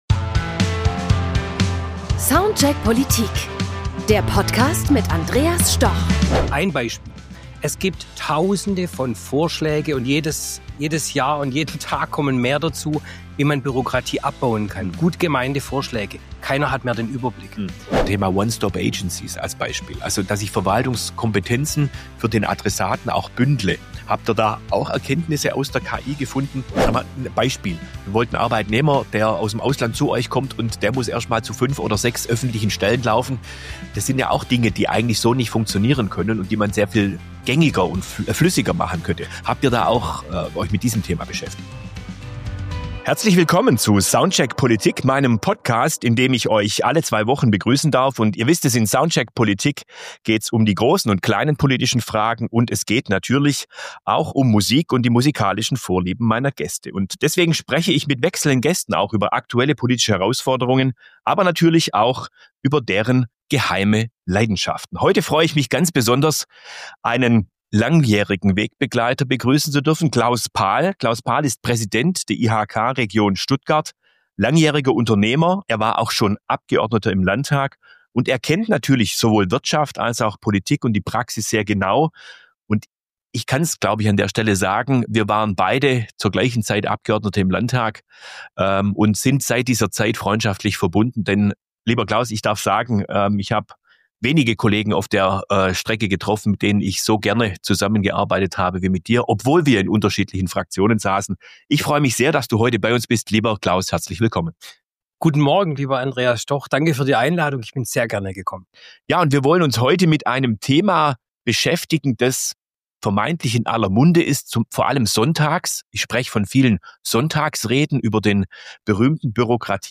In dieser Folge von Soundcheck Politik spricht SPD-Fraktionsvorsitzender Andreas Stoch mit Claus Paal, Präsident der IHK Region Stuttgart, langjähriger Unternehmer und früherer Landtagsabgeordneter.